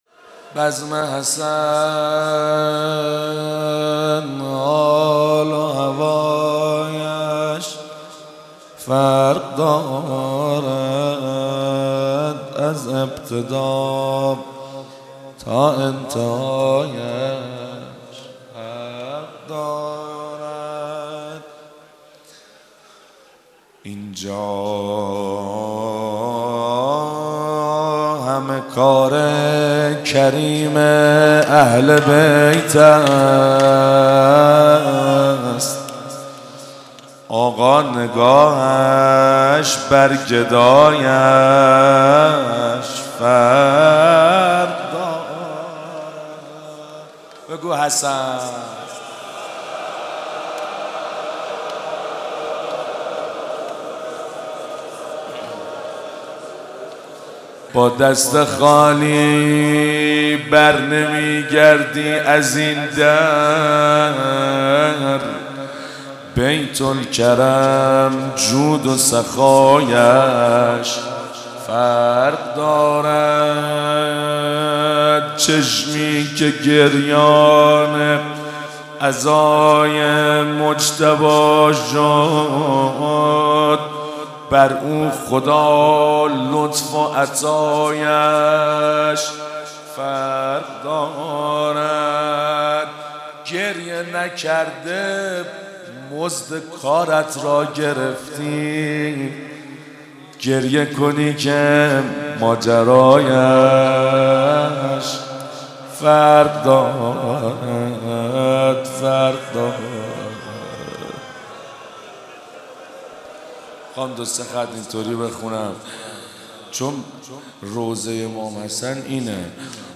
روضه | بزم حسن حال هوایش فرق دارد
در شب پنجم محرم 1394 | هیأت الرضا(ع) محفل امت حزب الله کرج